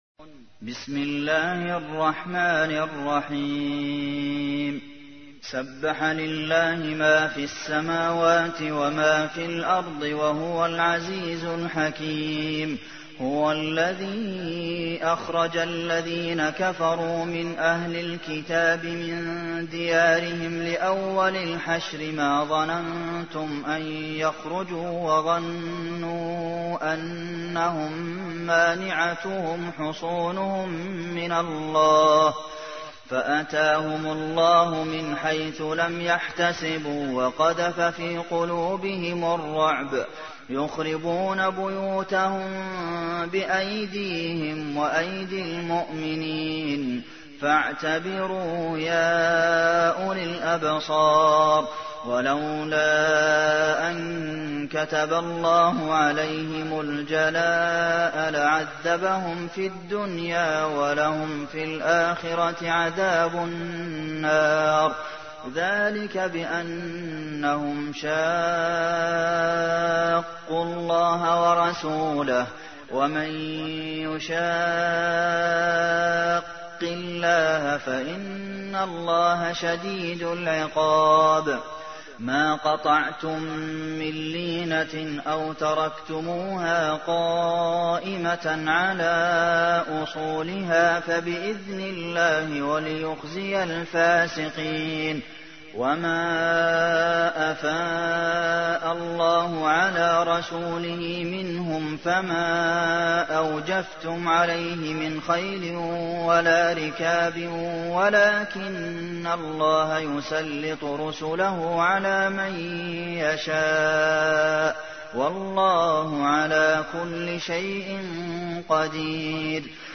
تحميل : 59. سورة الحشر / القارئ عبد المحسن قاسم / القرآن الكريم / موقع يا حسين